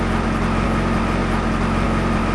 Diesel engine idle speed
Heavy truck or something similar on stand by.
diesel_engine-.wav